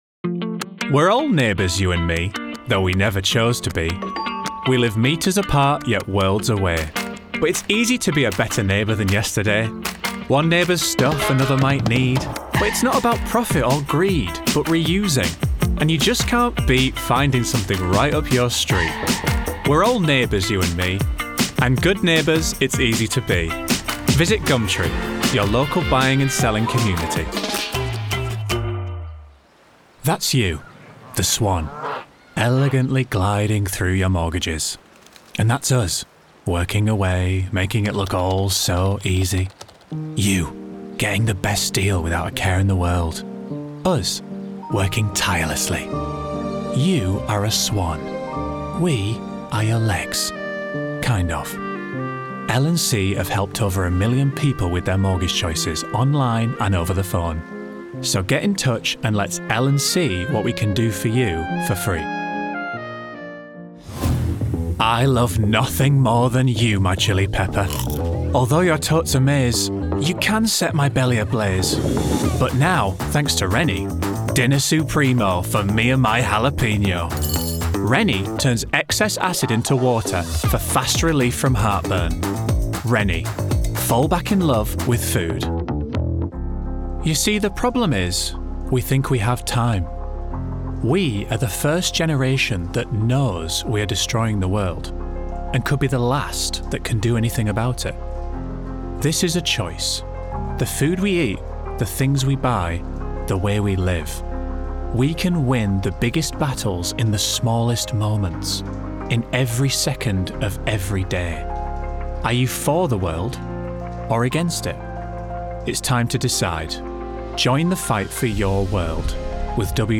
Commercial Showreel
Male
Yorkshire
Friendly
Playful